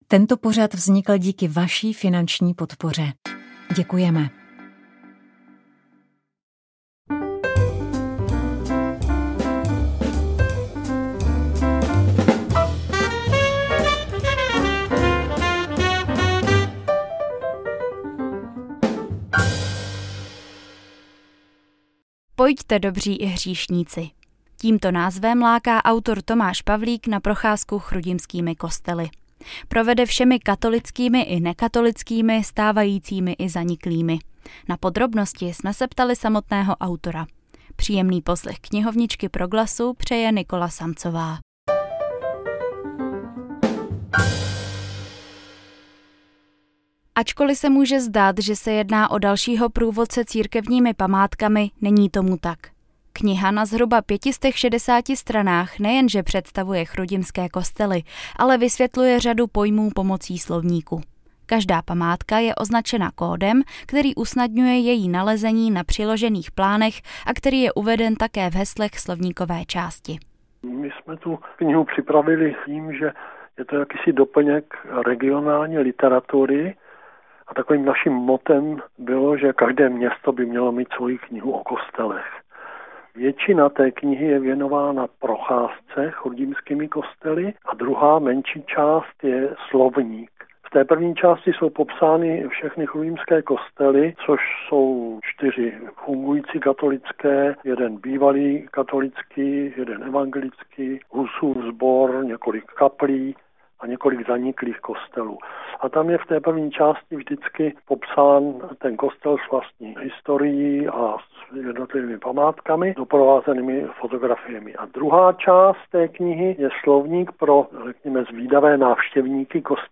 V našem pořadu nás s nimi osobně seznámí a v závěru nám rovněž zarecituje několik vybraných ukázek.